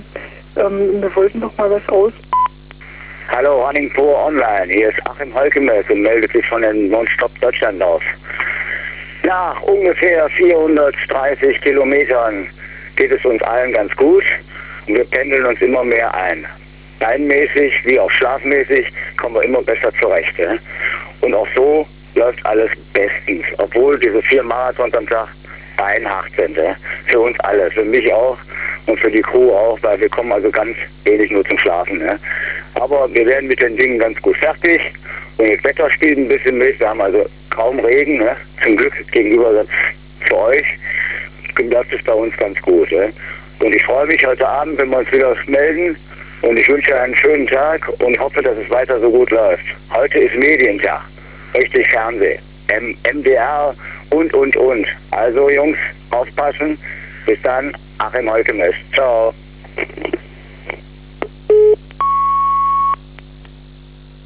Er hatte eine sehr harte Nacht. „4 Marathons am Tag – das ist das härteste, was ich je gemacht habe“, meint der Extremsportler in seinem Interview am frühen Morgen.